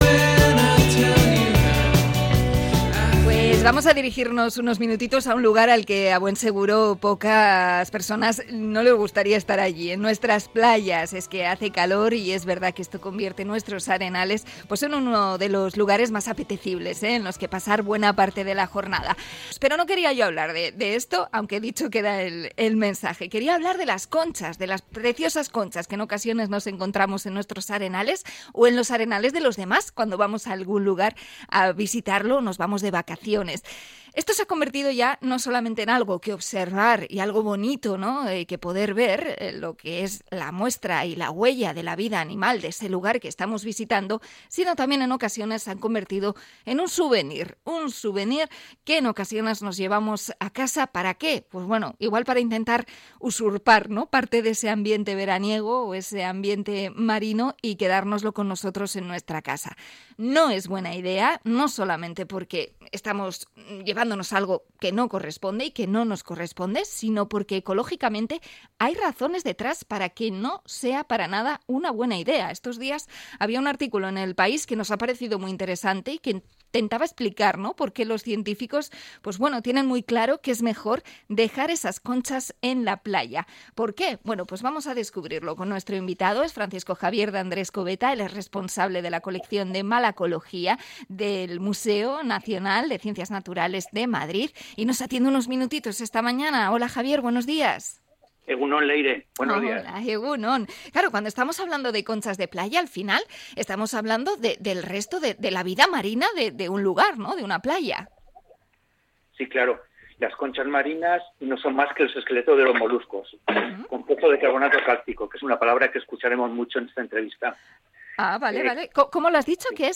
Entrevista a experto en moluscos del Museo Nacional de Ciencias